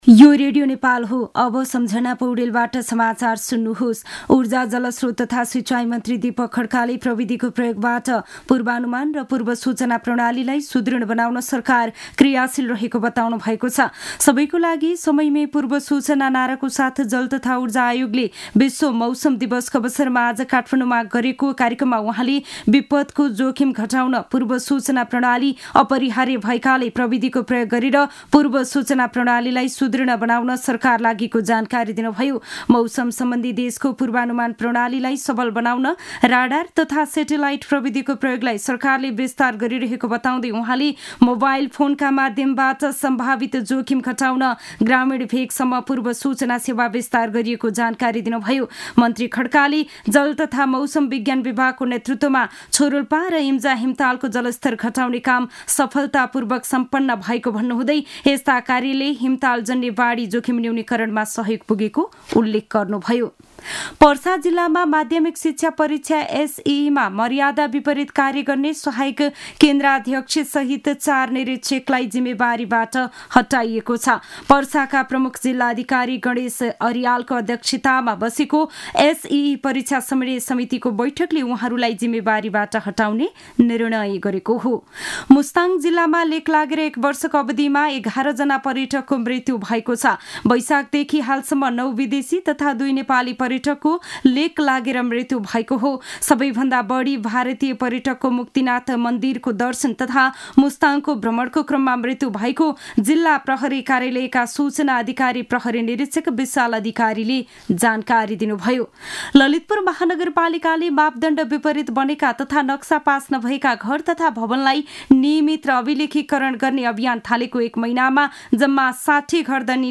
दिउँसो १ बजेको नेपाली समाचार : १० चैत , २०८१